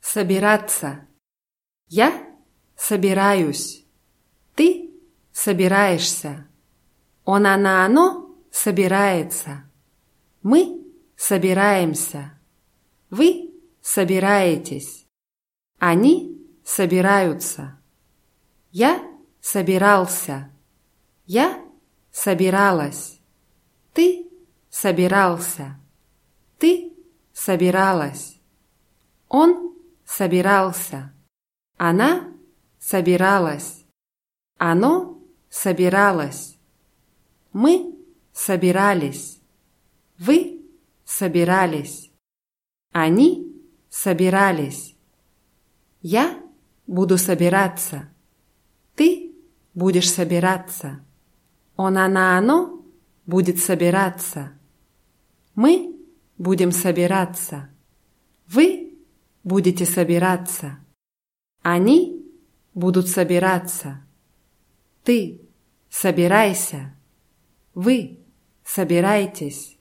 собираться [ßabʲirátsa]